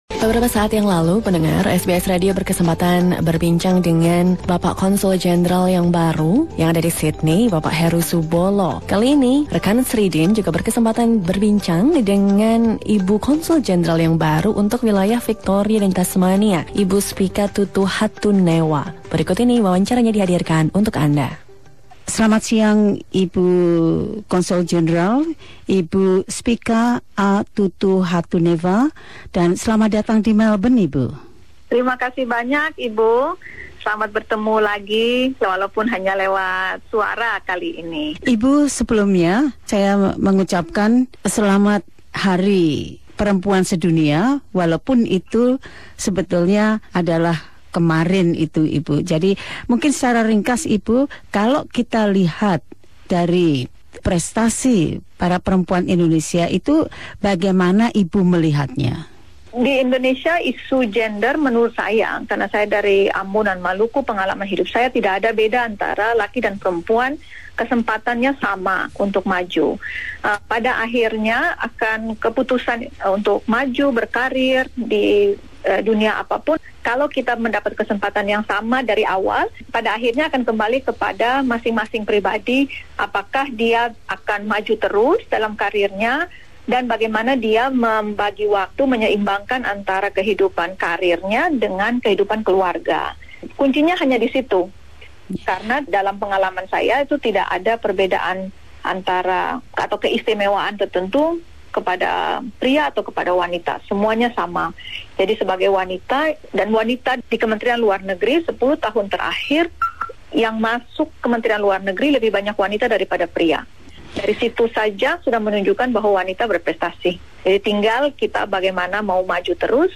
Konsul Jenderal Republik Indonesia untuk wilayah Victoria dan Tasmania, Spica A. Tutuhatunewa menyampaikan pesannya terkait dengan peringatan Hari Perempuan Sedunia. Dan kepada SBS beliau berbicara tentang harapannya untuk menerapkan program-programnya sebagai Konsul Jenderal Republik Indonesia yang baru untuk wilayah Victoria dan Tasmania.